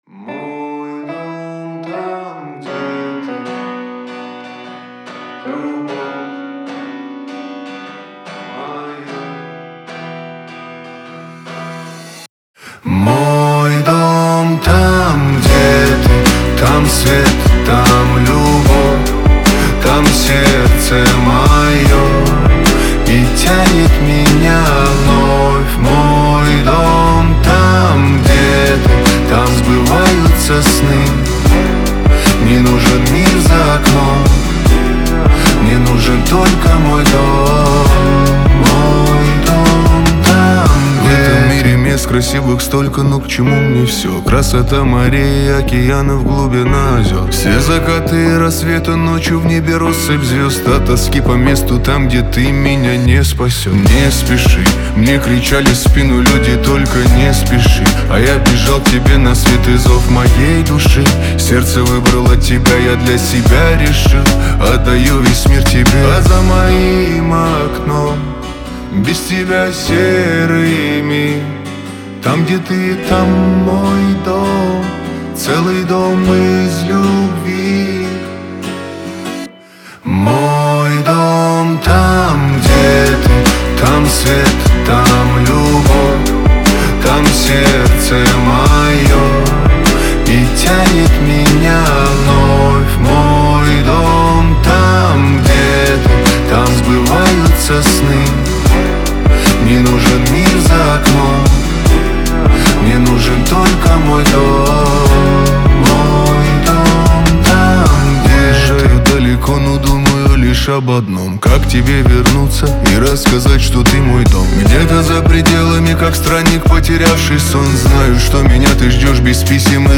диско
Шансон
Лирика